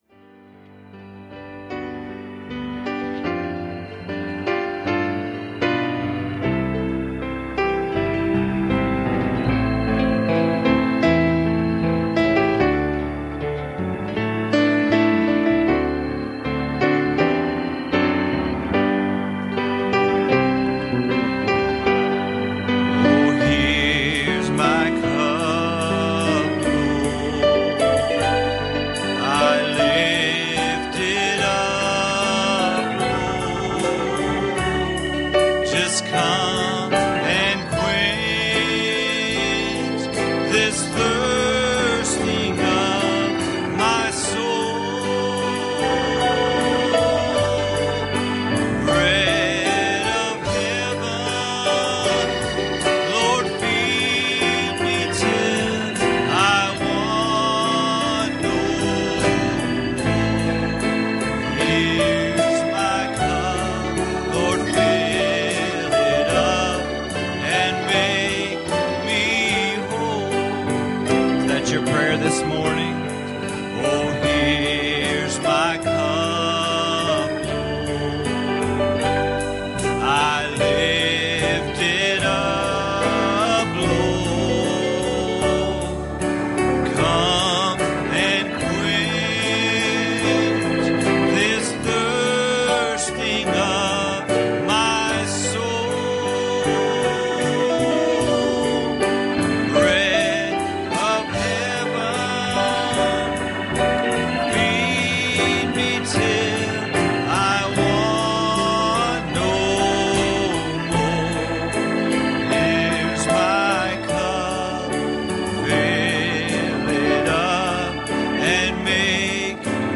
Jonah 2:8 Service Type: Sunday Morning "Symptoms